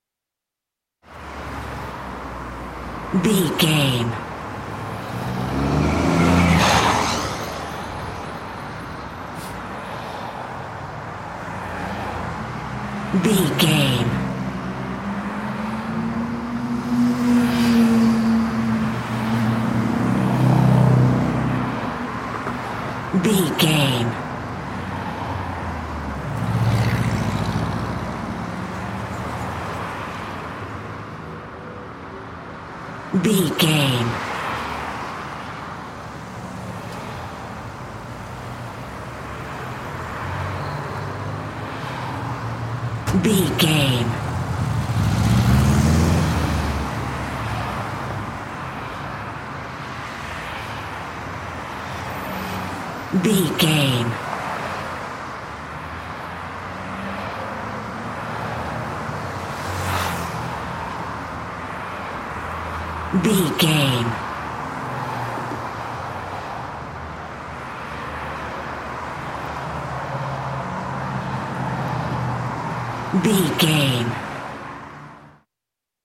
City avenue vehicles pass by
City avenue vehicles pass by 23
Sound Effects
urban
chaotic
ambience